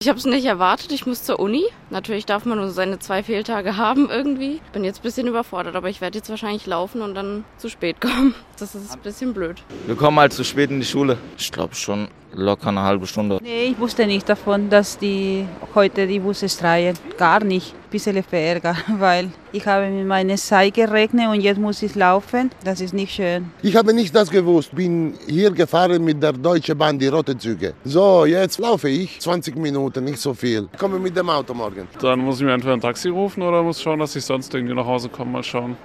Eine Umfrage an den Busbahnhöfen in Lahr und Offenburg am Mittwochmorgen: